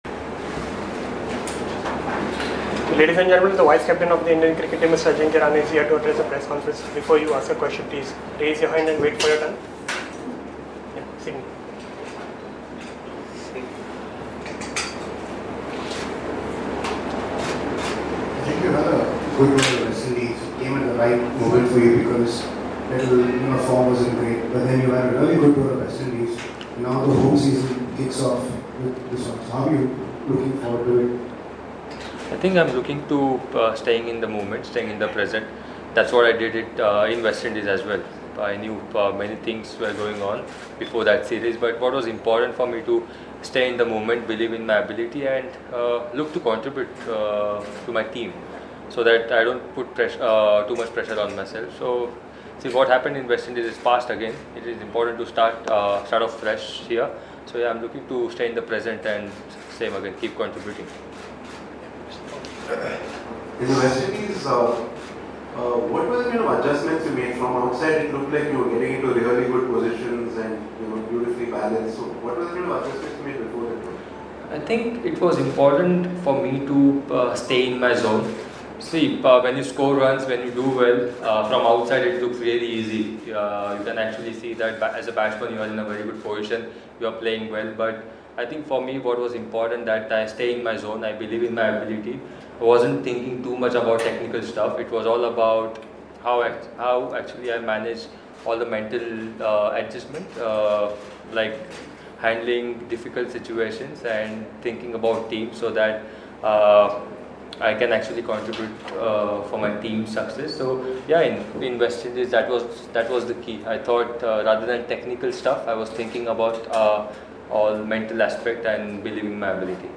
Ajinkya Rahane spoke to the media at the ACA-VDCA Stadium, Visakhapatnam after Team India's practice session on 1st Test.